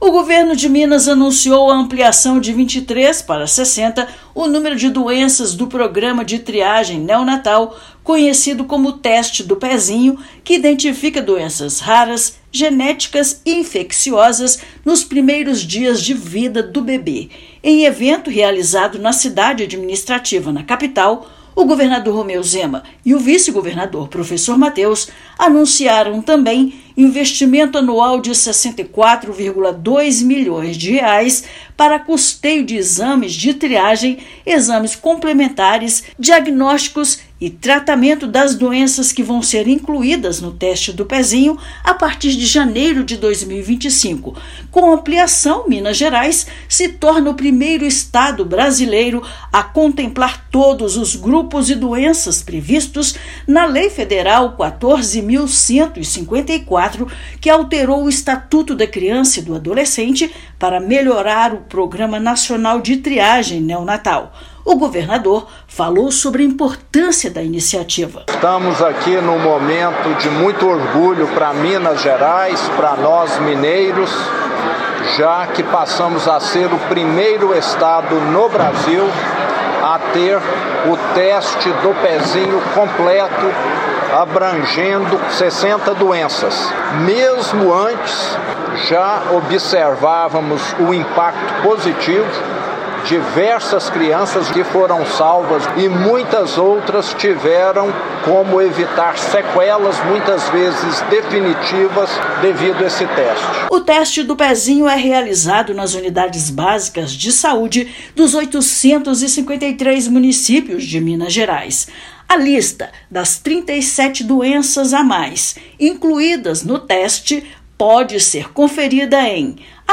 Número de condições triadas é inédito entre os estados brasileiros, com investimento estadual de mais de R$ 64 milhões anuais. Ouça matéria de rádio.